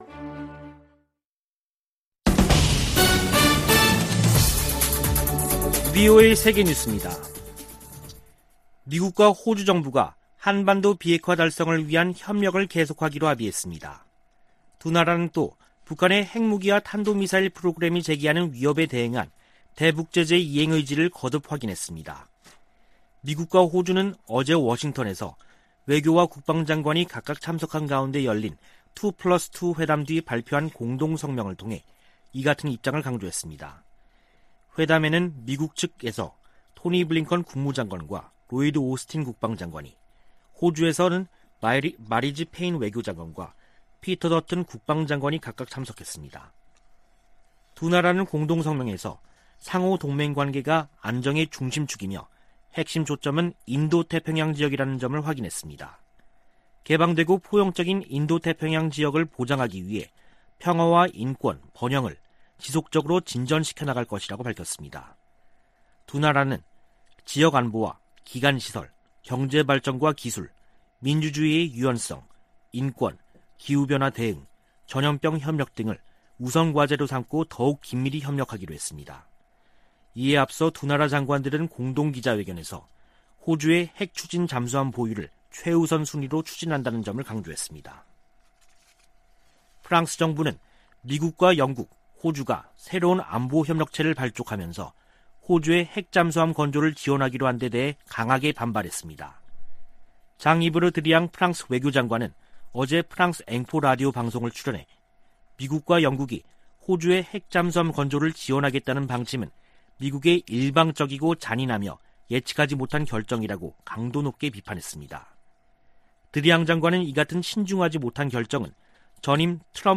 VOA 한국어 간판 뉴스 프로그램 '뉴스 투데이', 2021년 9월 17일 3부 방송입니다. 북한이 영변 우라늄 농축 시설을 확장하는 정황이 담긴 위성사진이 공개됐습니다. 76차 유엔총회가 14일 개막된 가운데 조 바이든 미국 대통령 등 주요 정상들이 어떤 대북 메시지를 내놓을지 주목됩니다. 유럽연합(EU)은 올해도 북한 인권 규탄 결의안을 유엔총회 제3위원회에 제출할 것으로 알려졌습니다.